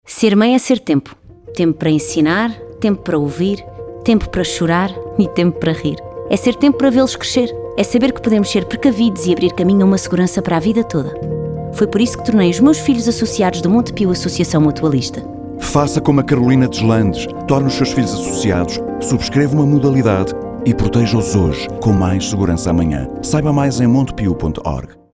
Spot da campanha de menores do Montepio AM já está no ar na Rádio Comercial